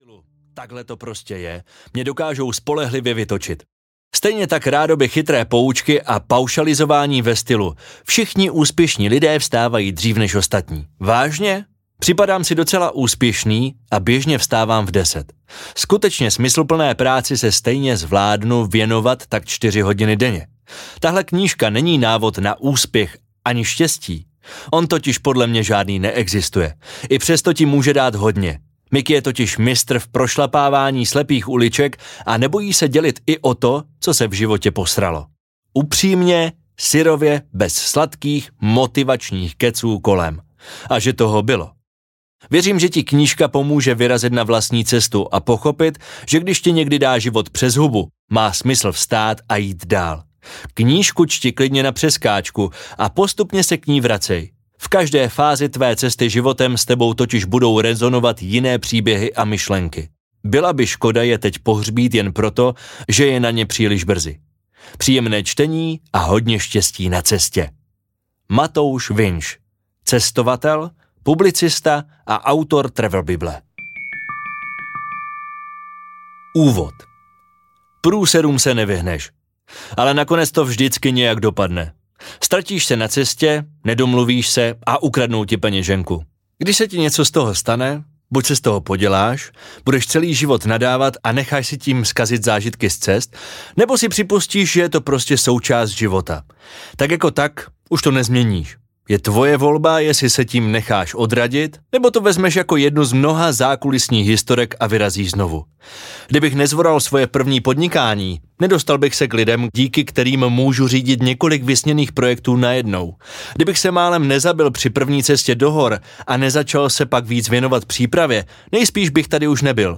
Audio knihaMůžeš (po)dělat cokoli
Ukázka z knihy